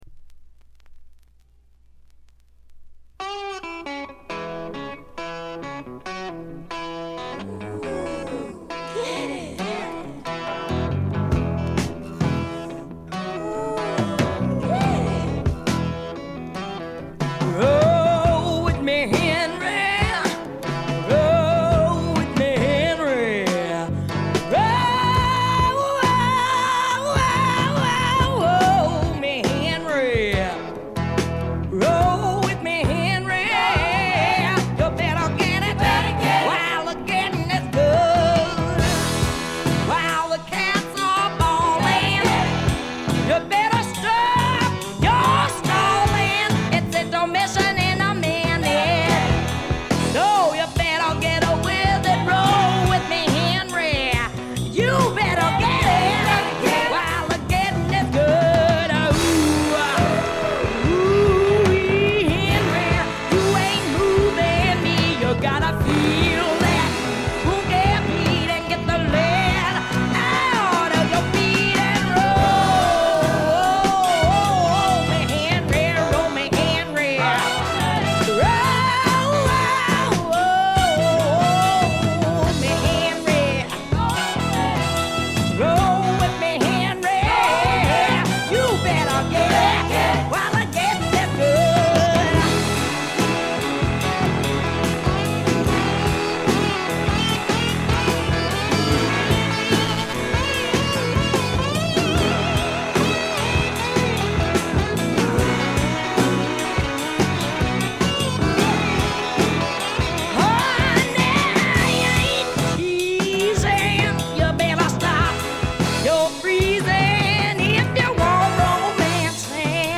ほとんどノイズ感無し。
フィメール・スワンプの大名盤です！
分厚いホーンセクションも心地よく決まって素晴らしいですね。
超重量級スワンプ名作。
試聴曲は現品からの取り込み音源です。
Vocals